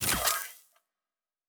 pgs/Assets/Audio/Sci-Fi Sounds/Weapons/Weapon 14 Reload 1 (Flamethrower).wav at master
Weapon 14 Reload 1 (Flamethrower).wav